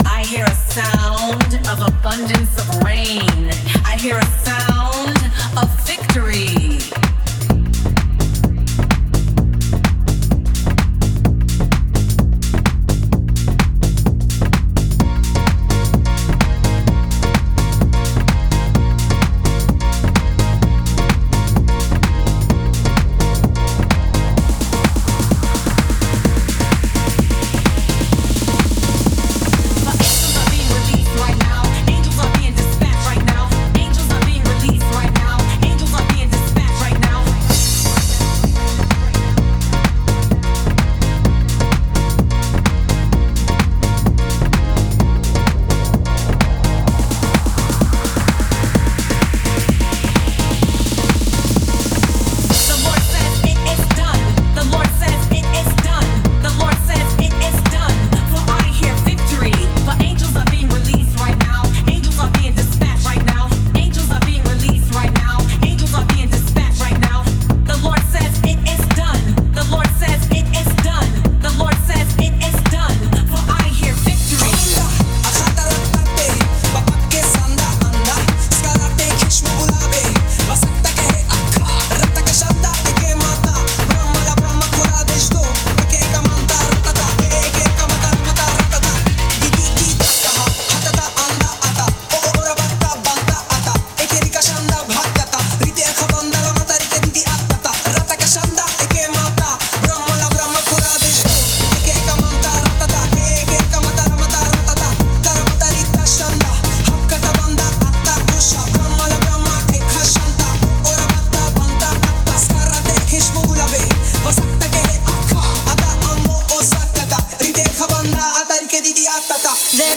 Paula White made me create a holy gibberish house track
In 2020, a video of Paula White, televangelist and spiritual advisor to Donald Trump, went viral as she passionately prayed for Trump’s reelection while speaking in tongues. The bizarre and rhythmic “mumbo jumbo” she uttered sparked both fascination and criticism.